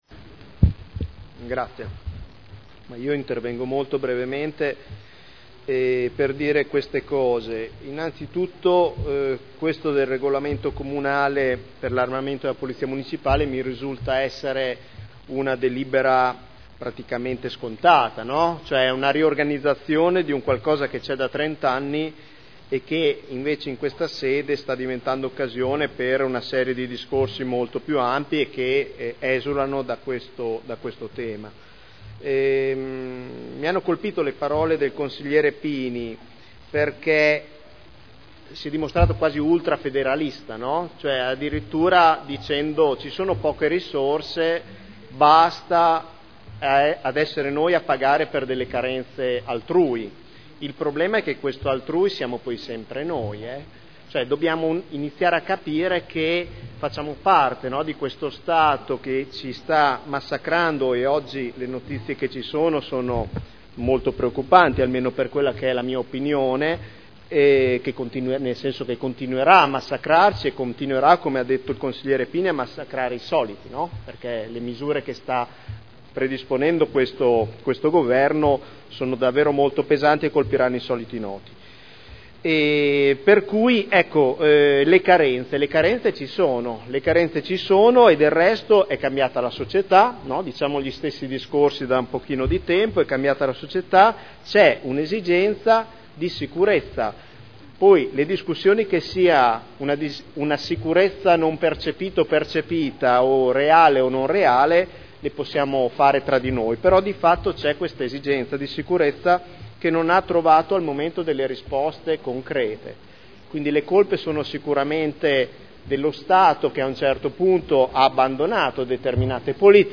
Dibattito.